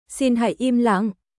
• シン ハイ イム ラン
Xin hãy im lặng.シン ハイ イム ラン静かにしてください（丁寧）